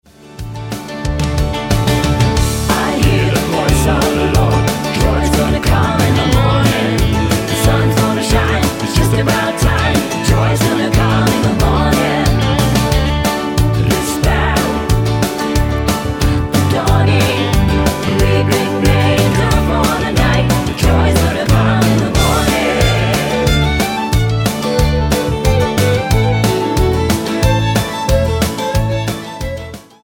--> MP3 Demo abspielen...
Tonart:D-Eb-E mit Chor